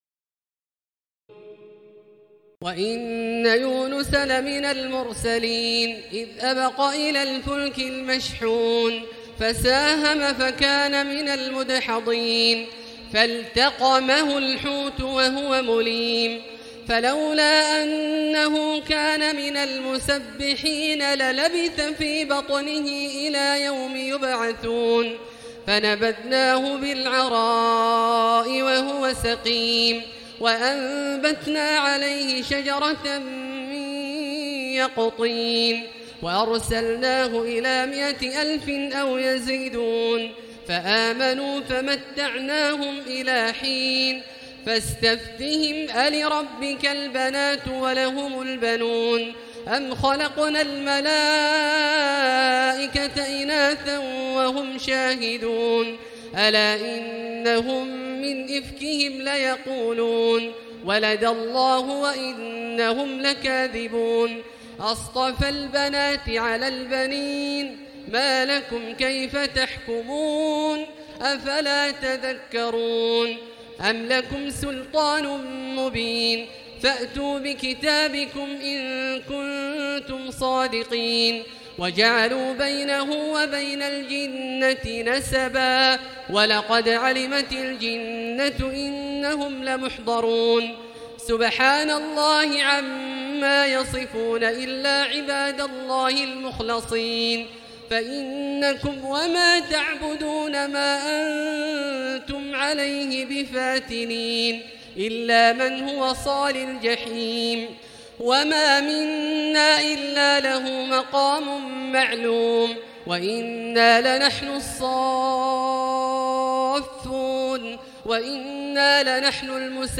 تراويح ليلة 22 رمضان 1439هـ من سور الصافات (139-182) وص و الزمر (1-31) Taraweeh 22 st night Ramadan 1439H from Surah As-Saaffaat and Saad and Az-Zumar > تراويح الحرم المكي عام 1439 🕋 > التراويح - تلاوات الحرمين